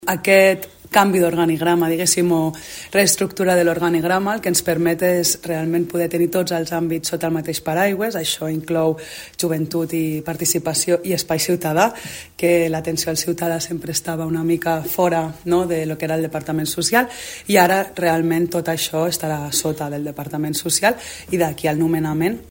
La consellera de Social, Joventut i Espai Ciutadà, Maria Nazzaro, ha explicat que el canvi respon a la voluntat d’agrupar sota un mateix paraigua tots els àmbits vinculats a l’atenció a les persones.